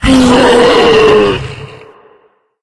48d440e14c Divergent / mods / Soundscape Overhaul / gamedata / sounds / monsters / bloodsucker / die_4.ogg 19 KiB (Stored with Git LFS) Raw History
die_4.ogg